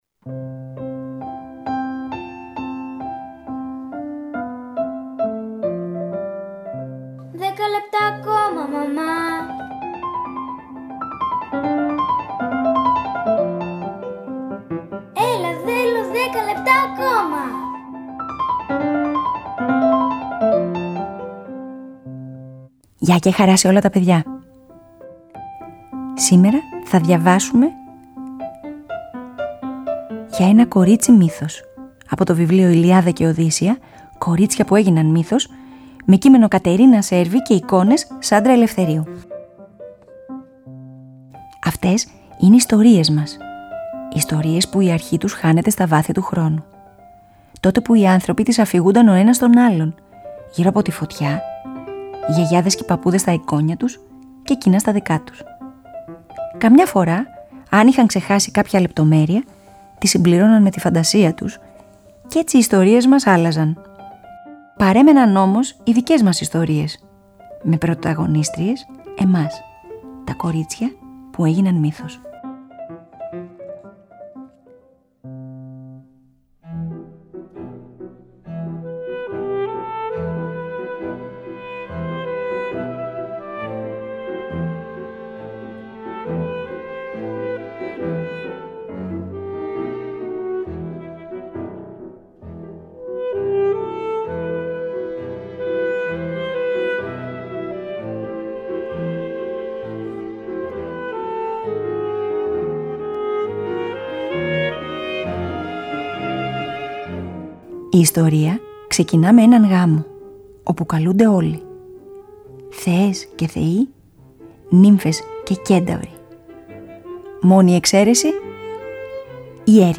Σήμερα διαβάζουμε μια ιστορία από ένα βιβλίο που μας γνωρίζει δεκαοκτώ μυθικές ηρωίδες –θεές και θνητές, νύμφες των δασών και των θαλασσών και μια μάγισσα– πιάνουν το νήμα του παραμυθιού, η μία μετά την άλλη, και αφηγούνται ιστορίες για μάχες και έρωτες, μοιραίες γνωριμίες και αιχμαλωσίες, θάρρος και αλτρουισμό, προδοσία και συγχώρεση.